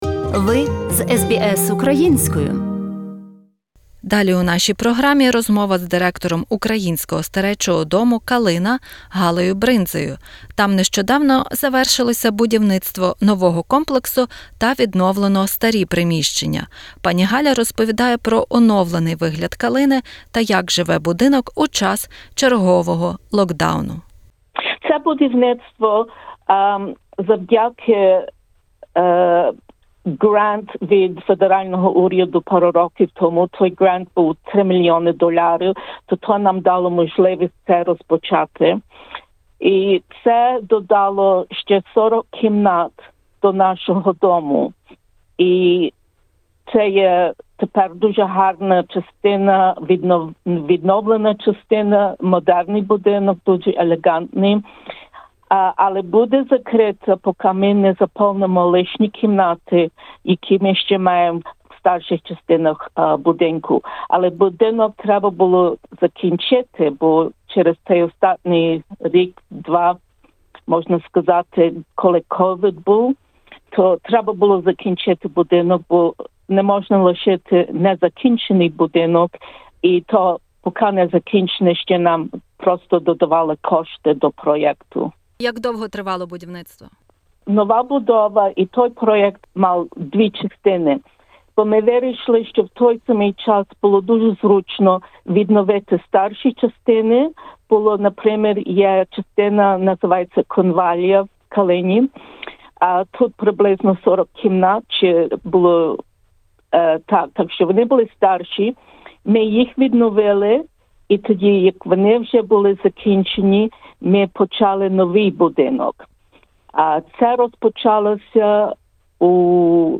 Розмова